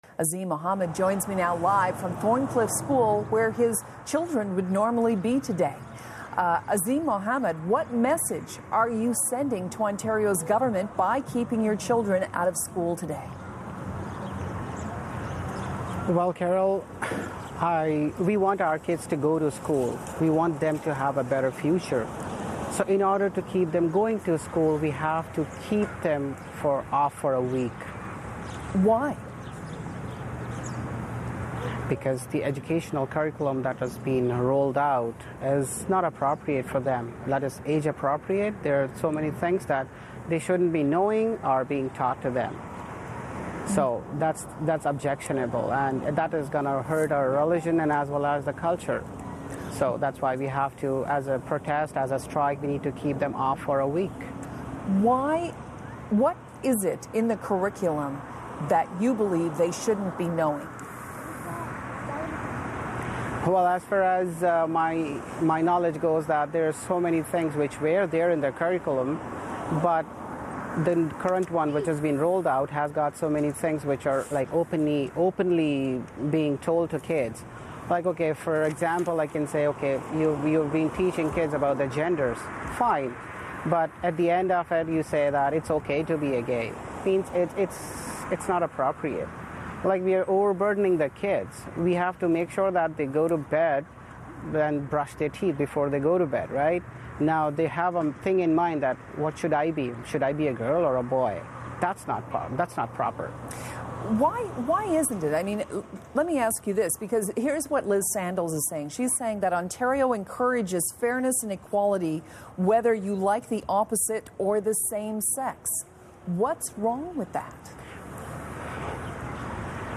加拿大广播公司采访家长：我们为什么反对安省新版性教育课程（英语）？